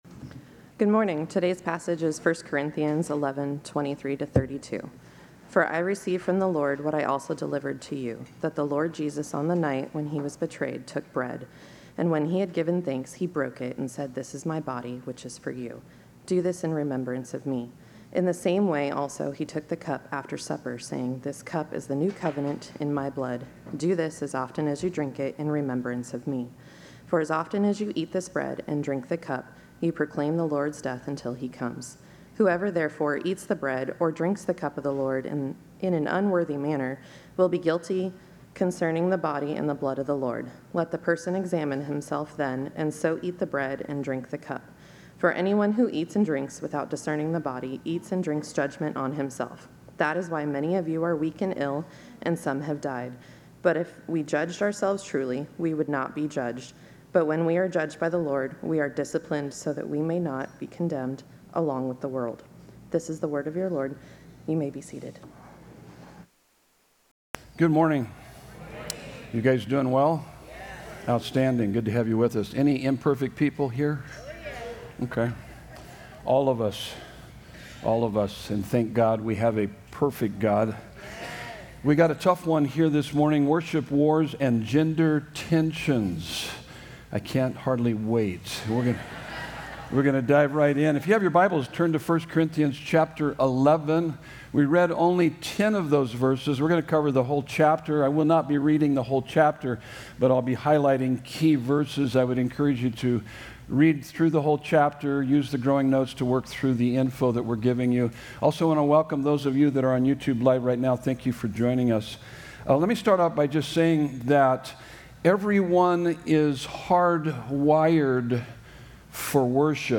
Sermon Notes: Worship Wars and Gender Tensions